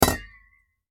Звук удара двух термосов